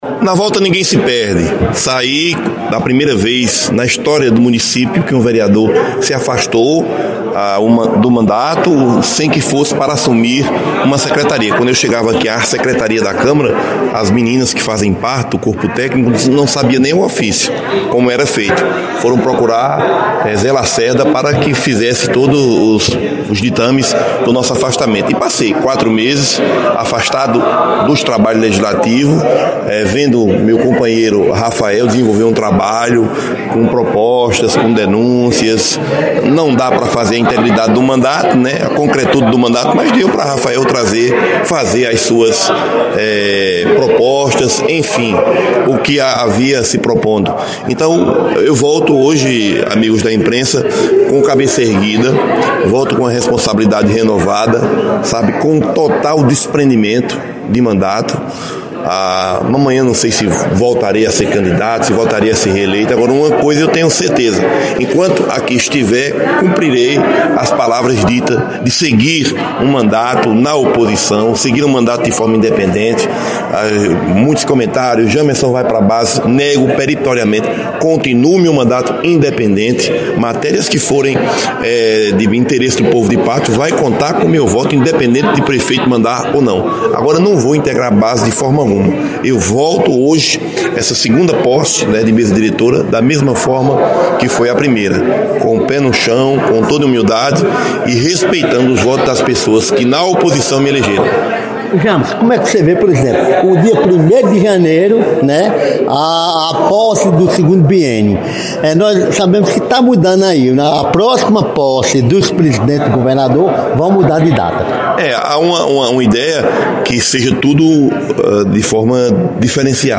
Durante coletiva de imprensa, o parlamentar disse que retorna à câmara de cabeça erguida por honrar o compromisso com o seu suplente, Rafael da Civil.